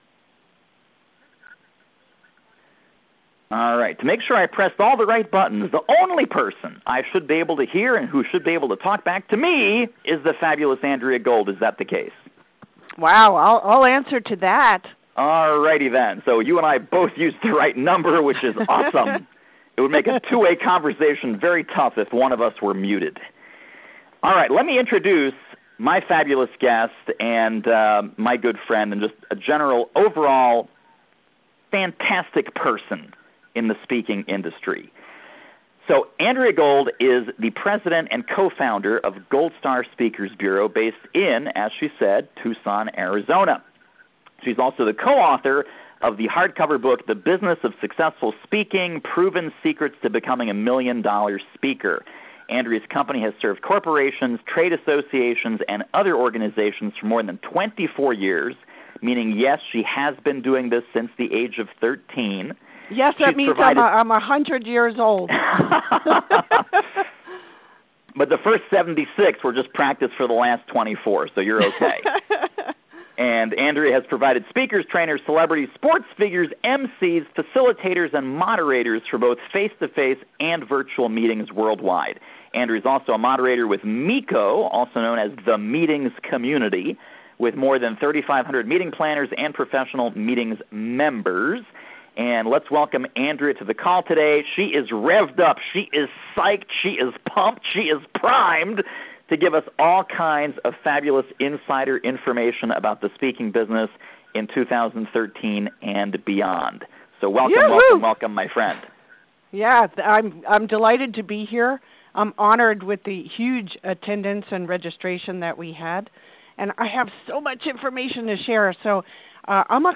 Your Speaking Business in 2013. FREE Teleseminar